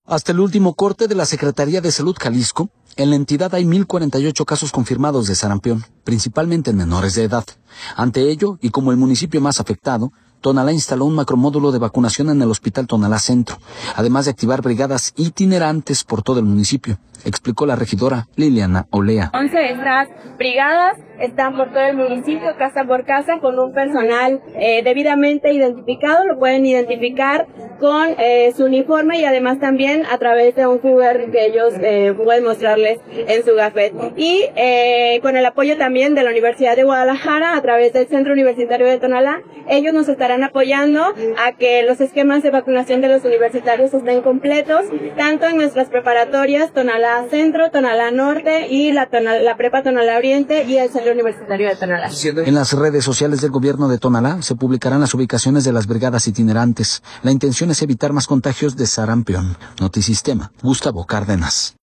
Ante ello, y como el municipio más afectado, Tonalá instaló un macro módulo de vacunación en el hospital Tonalá Centro, además de activar brigadas itinerantes por todo el municipio, explicó la regidora Liliana Olea.